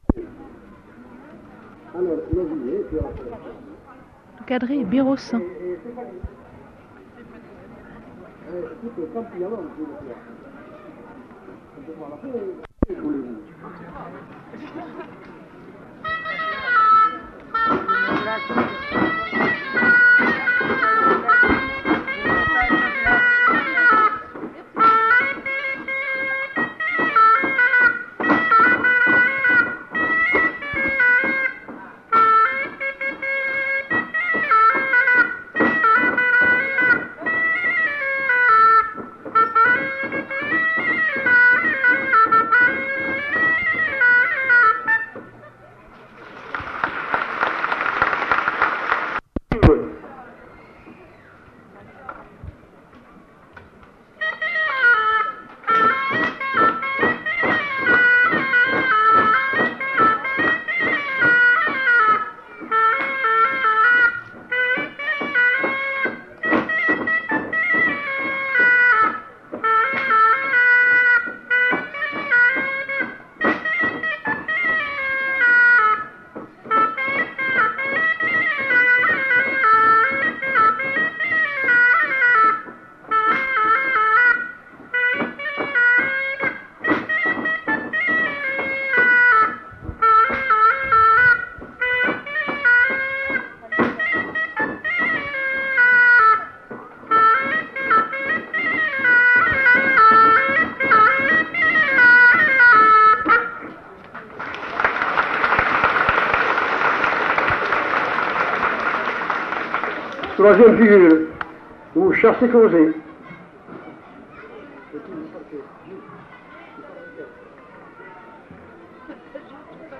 Aire culturelle : Couserans
Lieu : Argelès-Bagnères
Genre : morceau instrumental
Instrument de musique : hautbois
Danse : quadrille